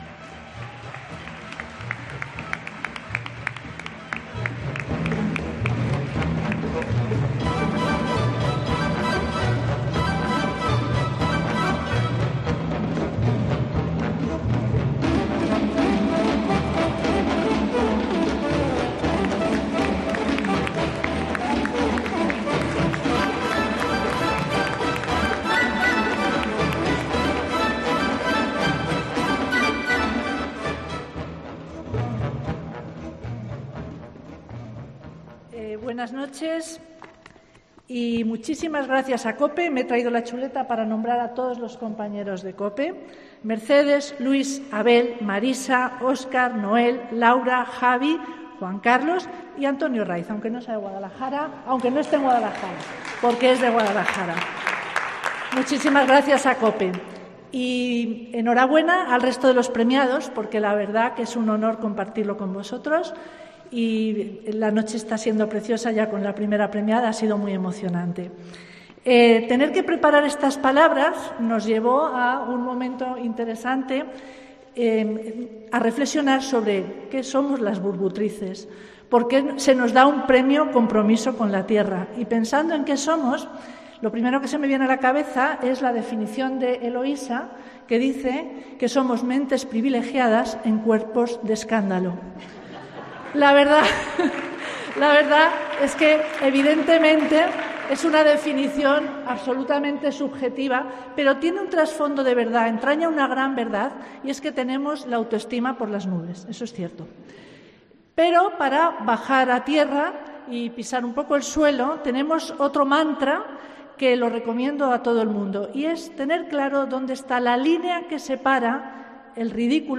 AUDIO: Discurso